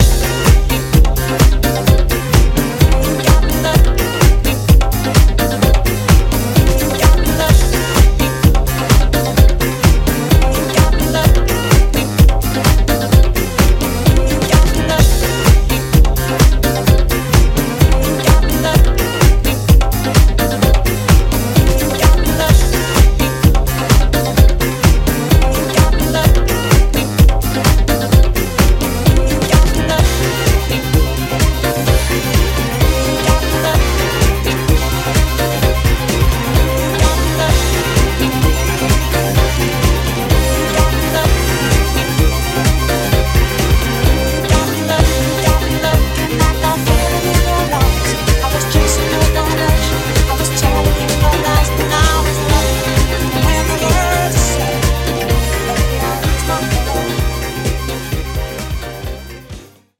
ジャンル(スタイル) DISCO HOUSE